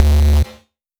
Phat Retro Bass 002.wav